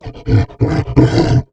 MONSTERS_CREATURES
MONSTER_Exhausted_07_mono.wav